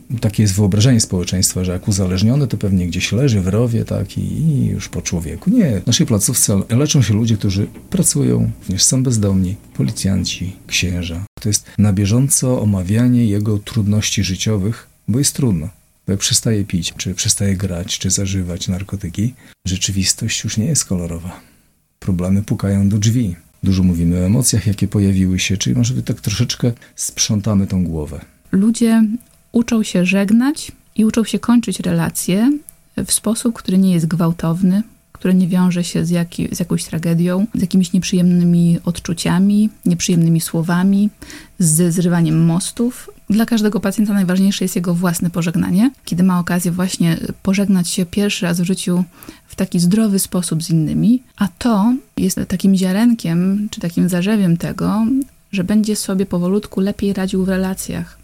O tym co nas może spotkać w takiej grupie, ale też o mitach na ten temat rozmawialiśmy w niedzielę (14.09) w audycji ,,Trzeźwe rozmowy o uzależnieniach”.